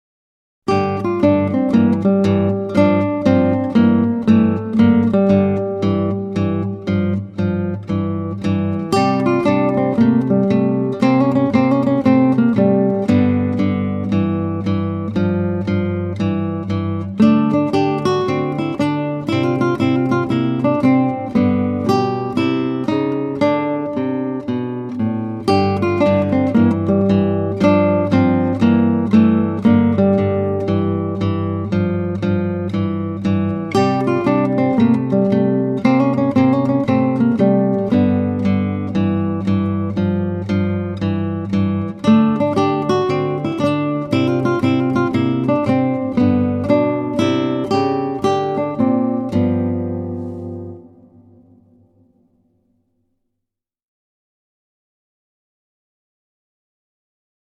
1 / 2 gitaren
29 pieces for Guitar.